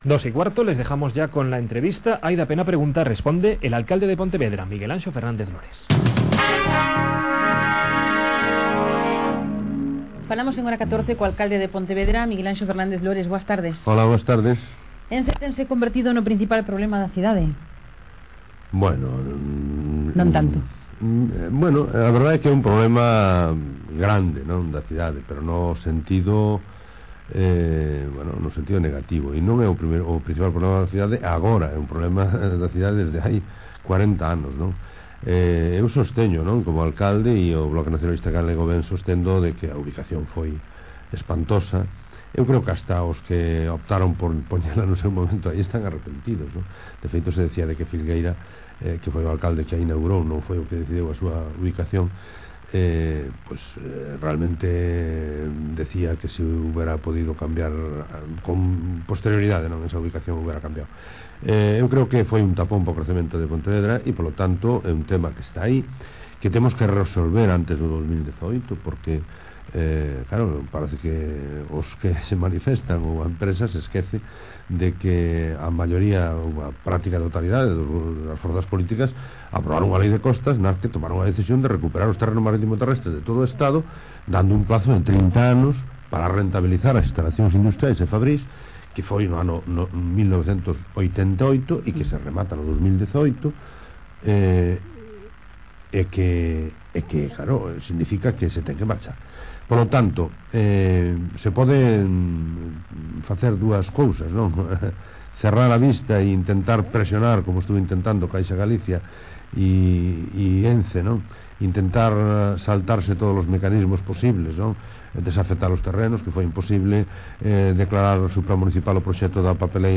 Entrevista a Miguel Anxo Fern�ndez Lores, presidente da C�mara Municipal de Ponte Vedra. Cadena SER, Compostela, 27 Mar�o 2005.